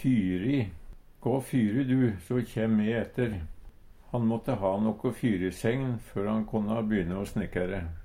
fyri - Numedalsmål (en-US)